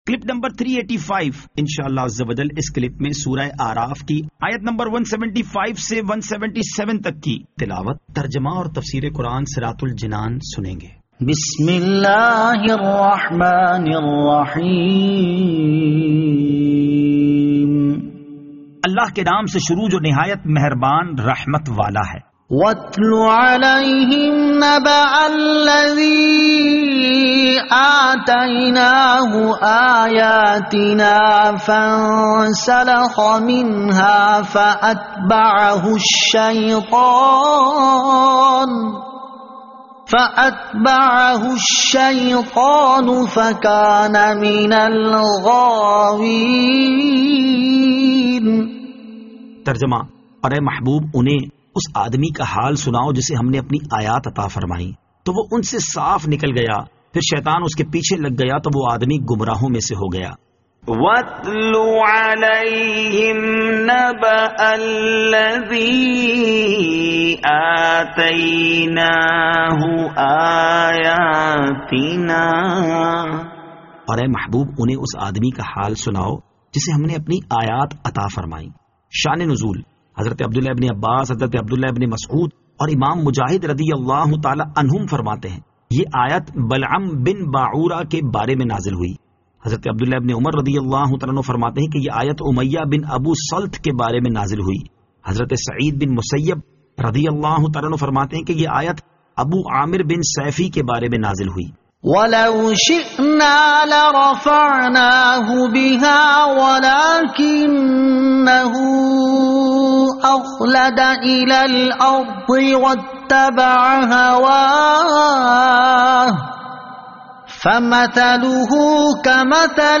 Surah Al-A'raf Ayat 175 To 177 Tilawat , Tarjama , Tafseer